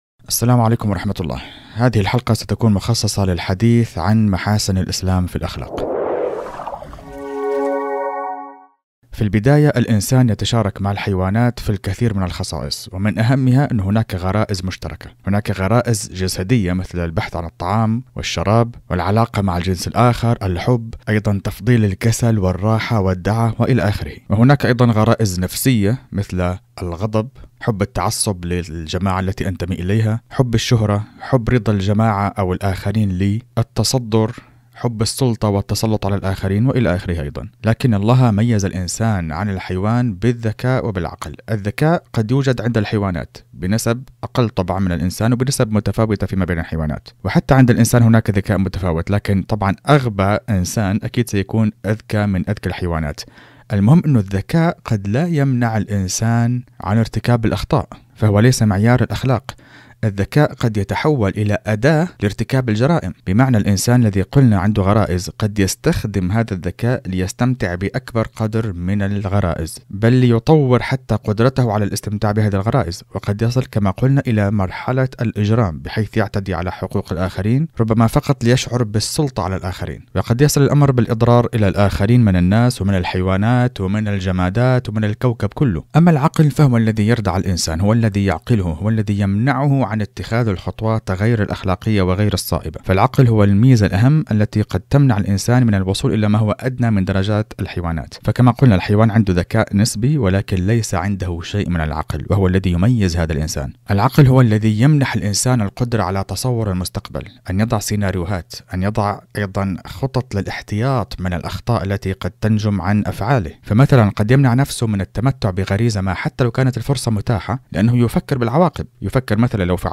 محاسن الإسلام في الأخلاق- محاضرة